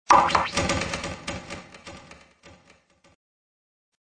Descarga de Sonidos mp3 Gratis: bateria 1.